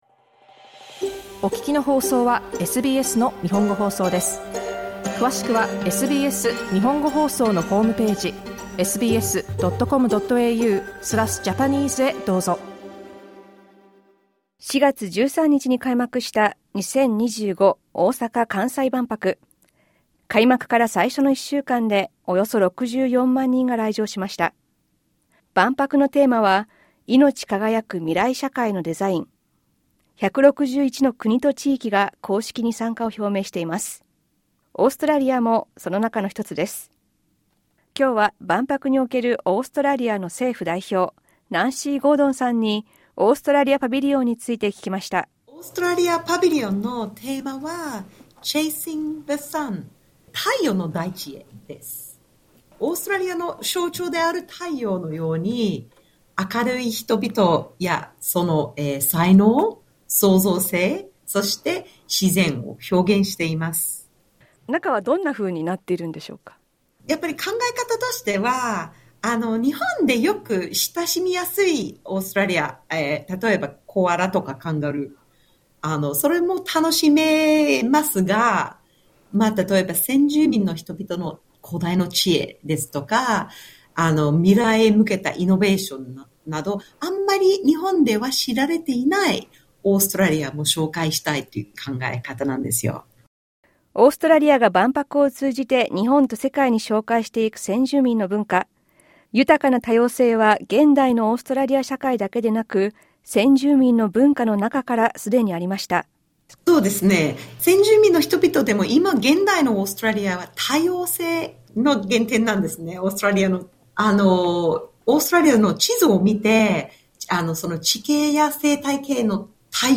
万博で政府代表を務めるナンシー・ゴードンさんに、オーストラリア・パビリオンの内容などについて聞きました。